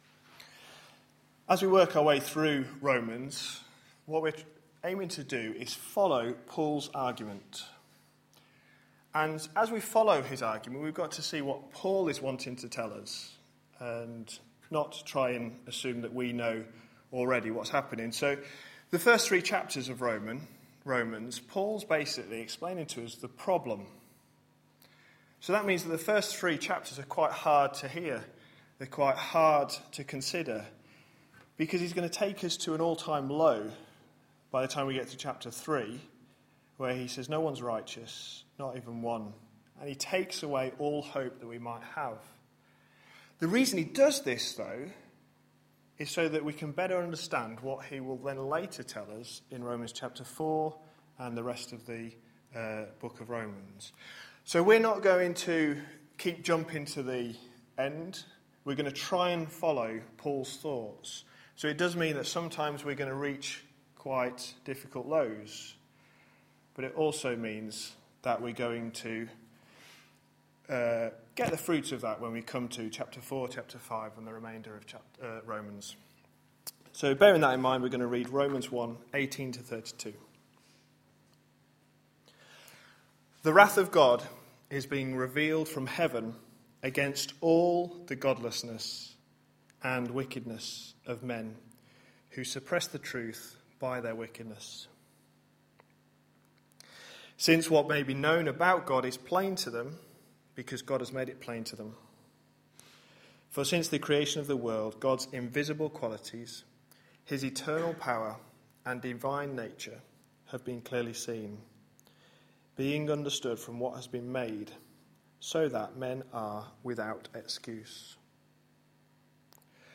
A sermon preached on 7th October, 2012, as part of our Romans series.